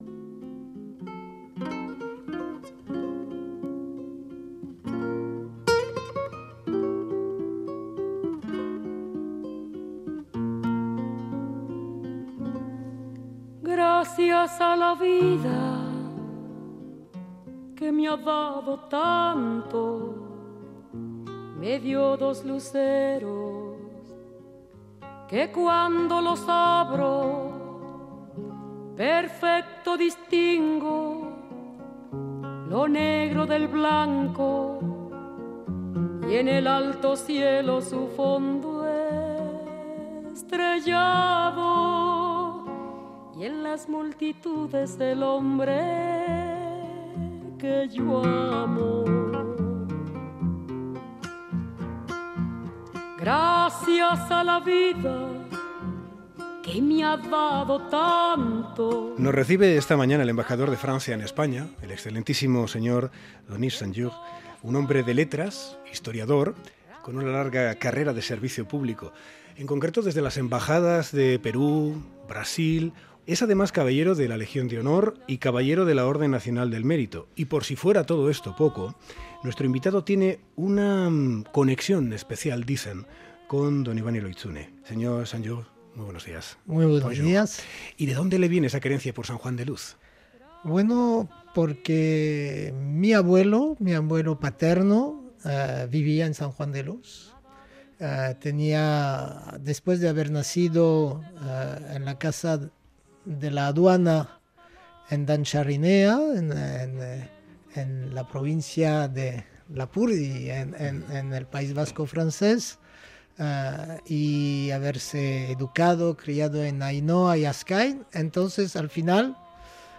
Ives Saint-Geours, embajador de Francia en Más que Palabras